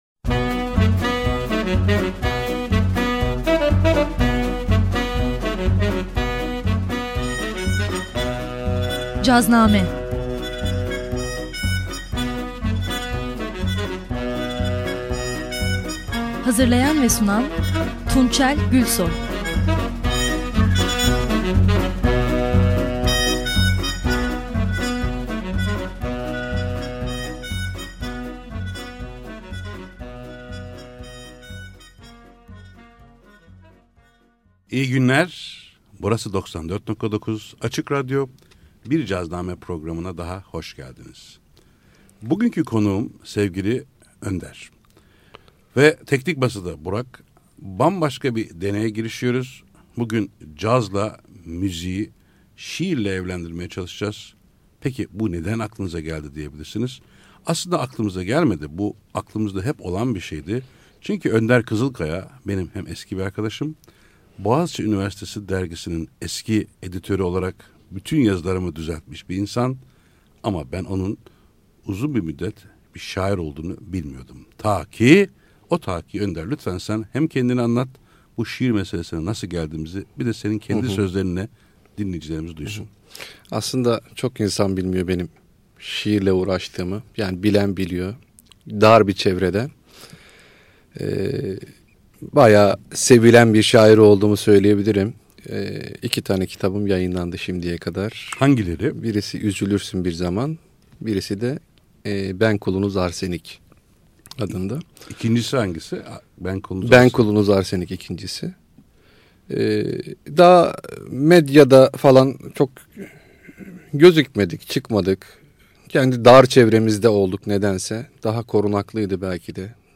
Açık Radyo söyleşi